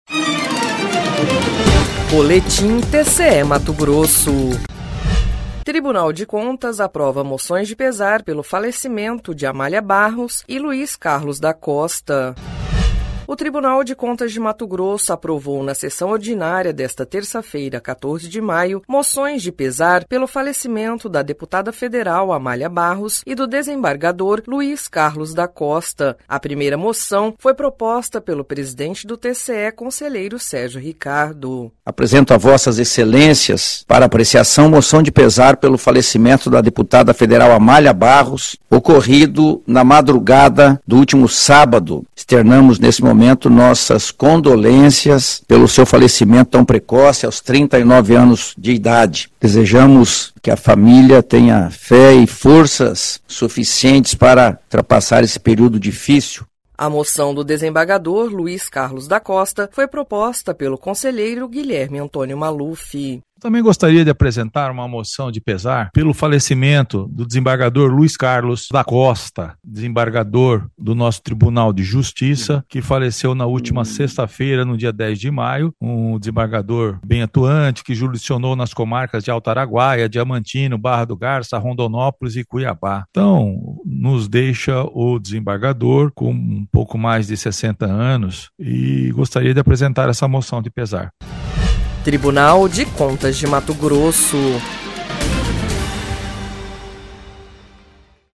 Sonora: Sérgio Ricardo – conselheiro-presidente do TCE-MT
Sonora: Guilherme Antonio Maluf – conselheiro do TCE-MT